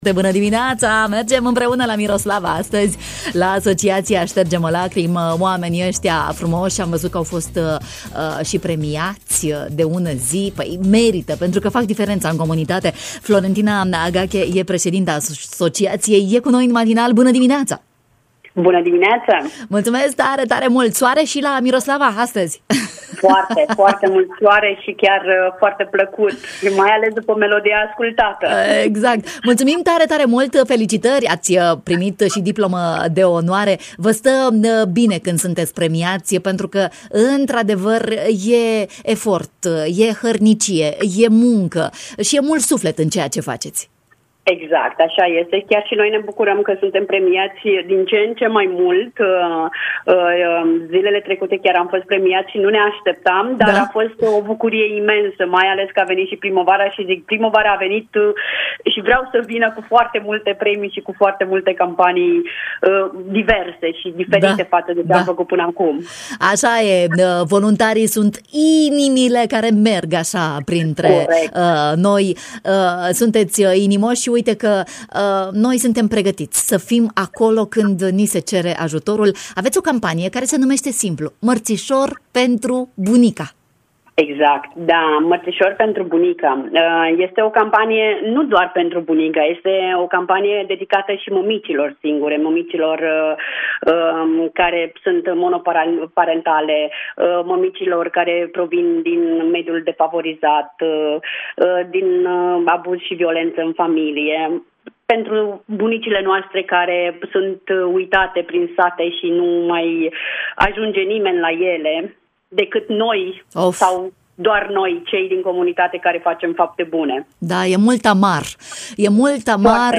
ne-a dat detalii în matinal.